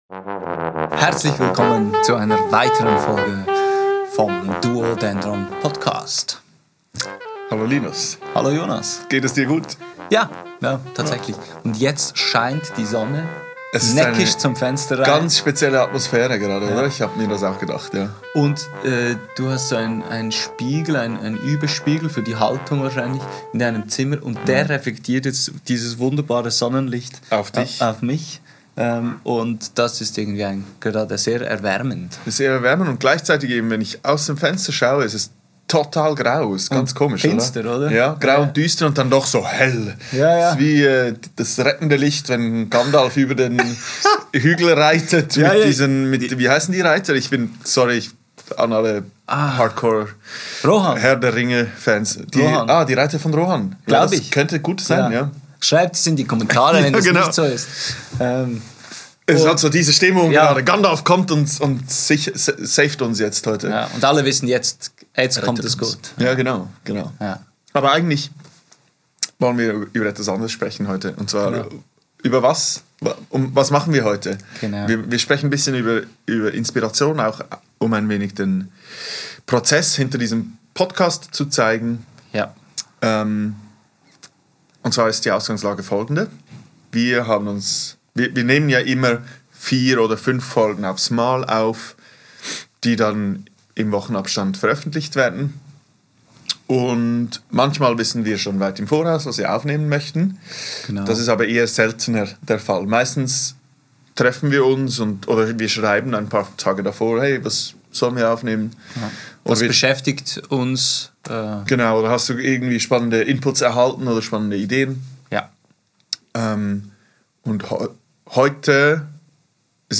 Aufgenommen am 18.09.2024 im Atelier